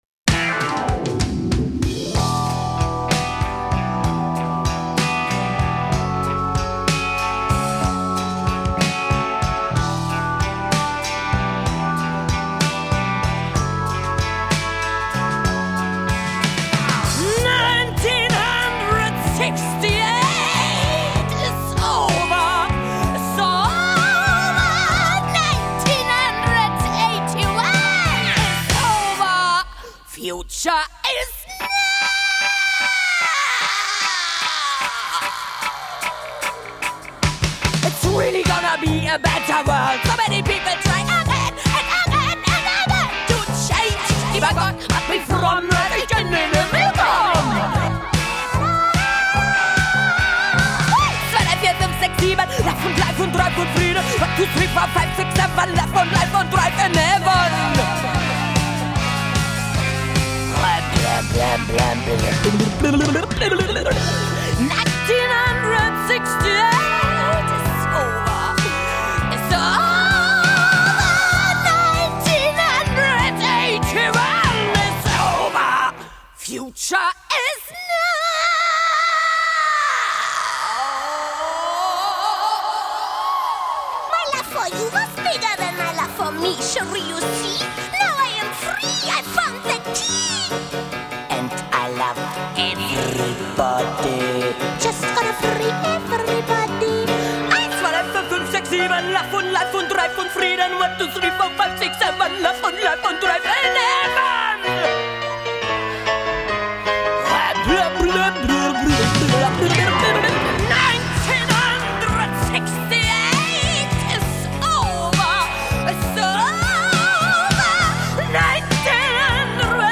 Панк Рок